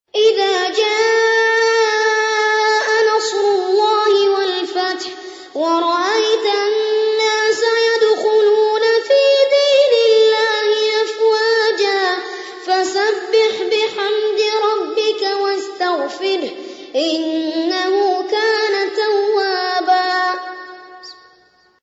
قارئ معتمد رواية ورش عن نافع
أحد أشهر قراء القرآن الكريم في العالم الإسلامي، يتميز بجمال صوته وقوة نفسه وإتقانه للمقامات الموسيقية في التلاوة.